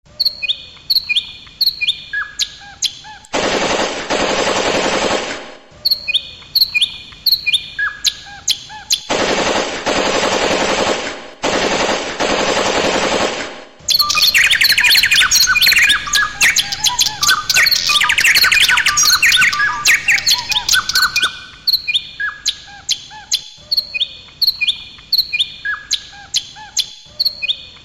laughing-birds-2.mp3